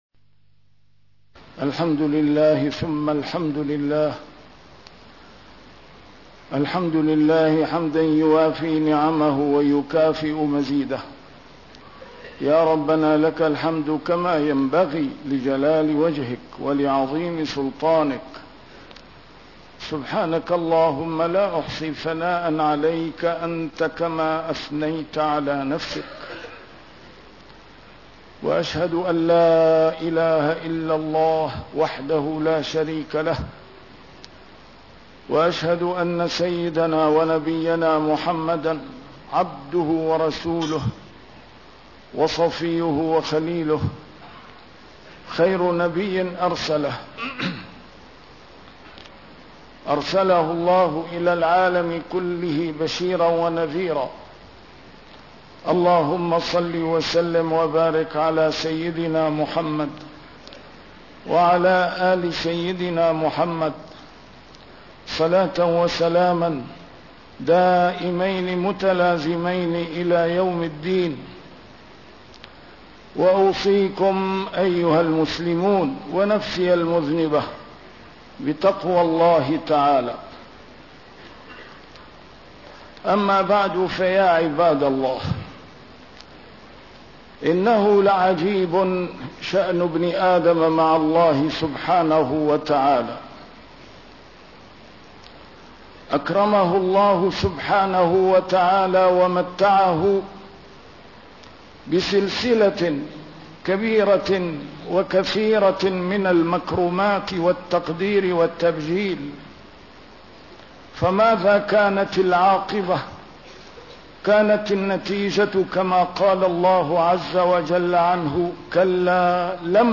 A MARTYR SCHOLAR: IMAM MUHAMMAD SAEED RAMADAN AL-BOUTI - الخطب - كلاَّ لما يقضِ ما أمره